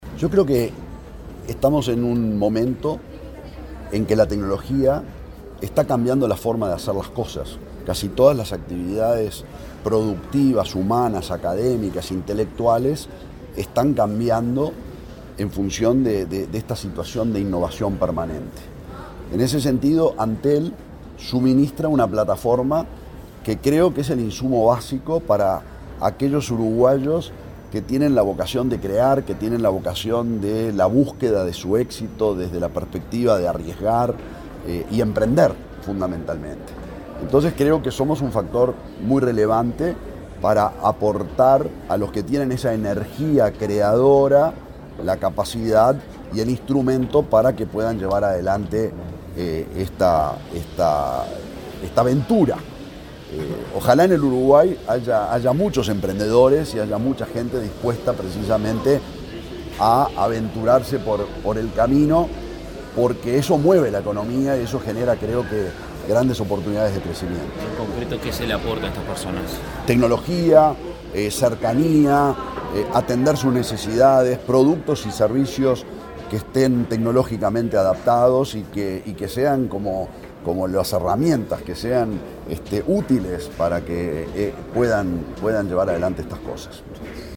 Declaraciones del presidente de Antel, Gabriel Gurméndez
Este martes 5, el presidente de Antel, Gabriel Gurméndez, dialogó con la prensa luego de participar en la apertura de un encuentro de emprendedores,